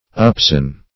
Search Result for " upsun" : The Collaborative International Dictionary of English v.0.48: Upsun \Up"sun`\, n. (Scots Law) The time during which the sun is up, or above the horizon; the time between sunrise and sunset.